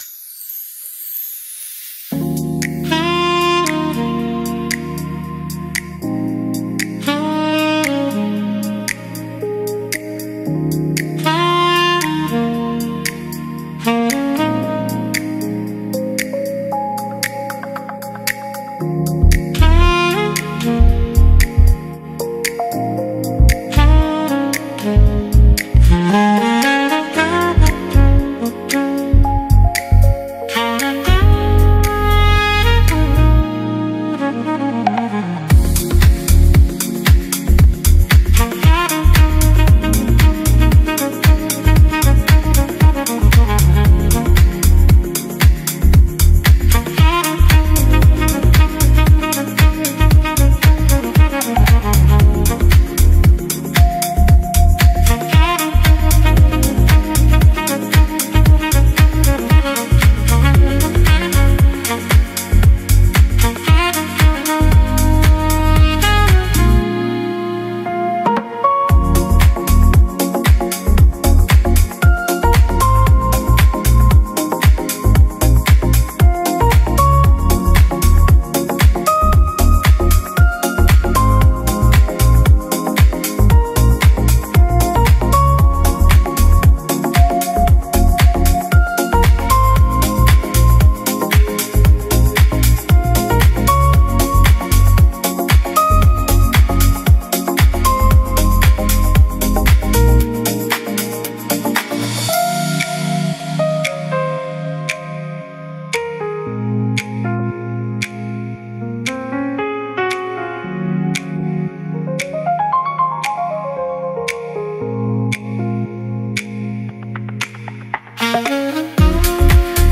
pause_music_01.mp3